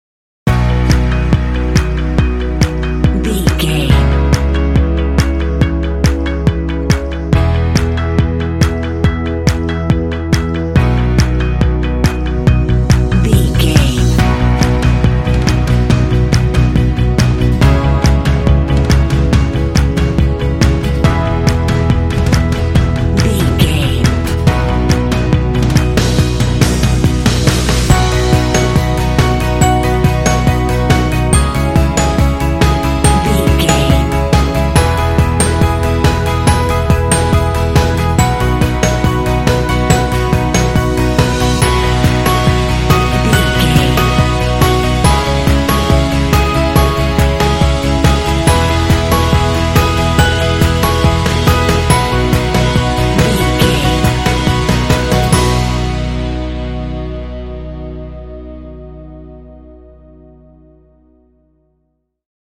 Ionian/Major
driving
joyful
electric guitar
bass guitar
drums
piano
pop
alternative rock